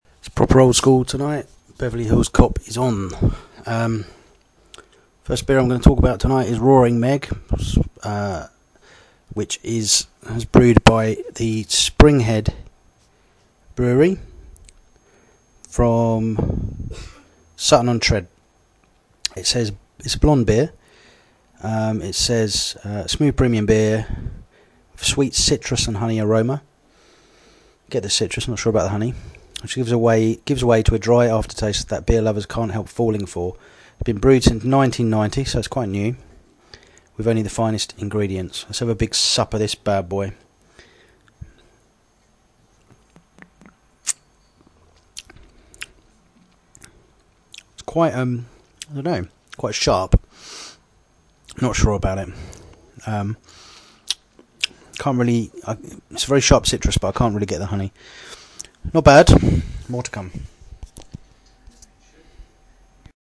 roaring pub
31731-roaring-pub.mp3